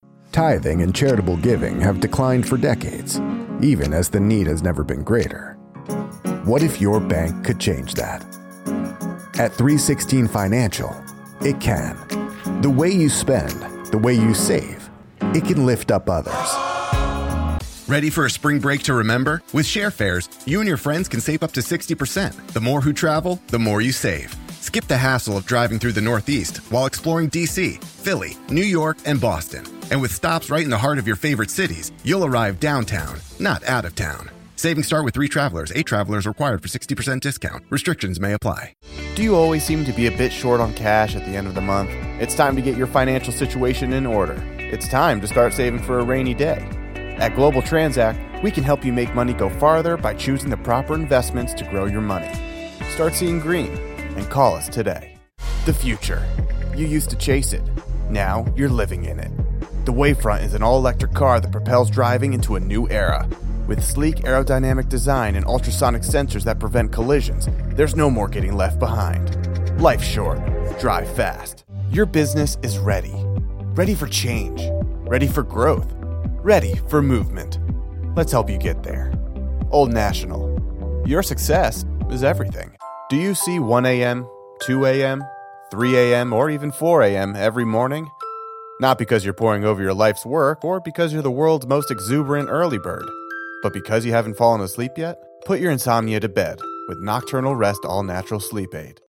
Energetic, expressive voices that capture the spirit of the FIFA Museum in Zurich. Hear bold commercial reads that bring football stories to life - listen now.